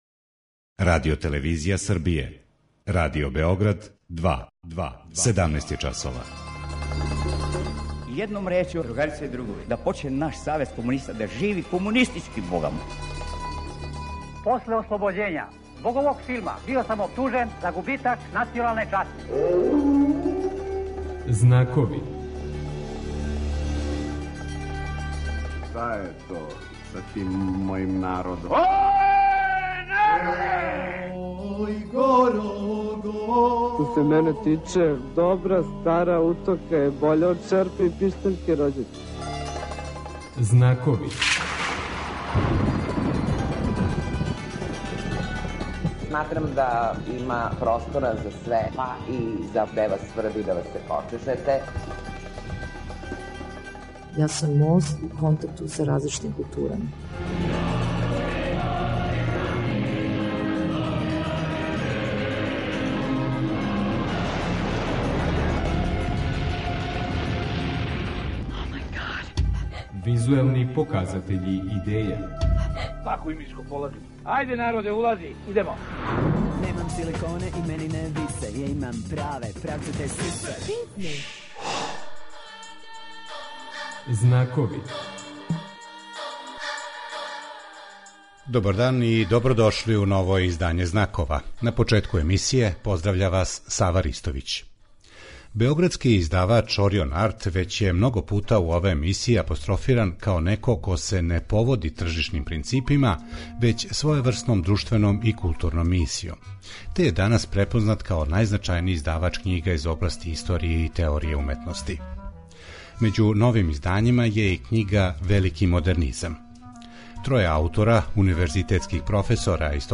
У Знаковима преносимо најзанимљивије детаље овог изузетно посећеног разговора.